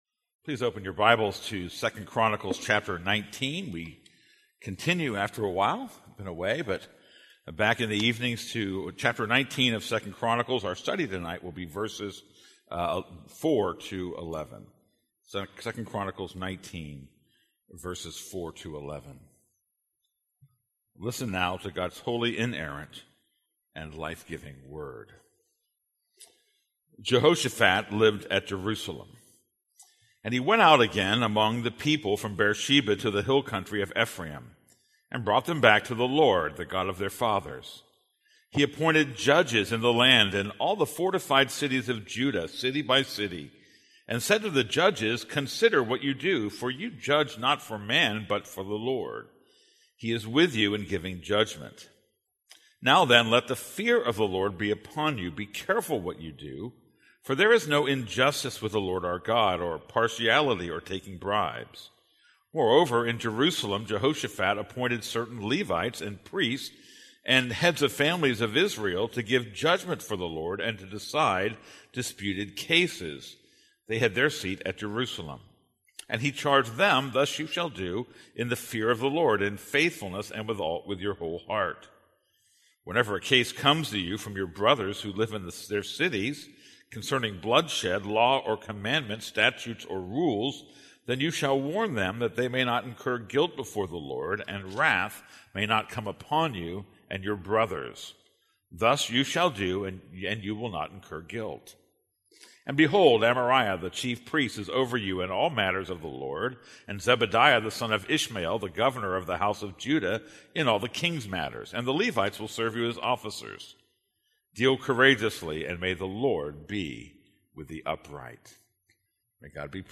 This is a sermon on 2 Chronicles 19:4-11.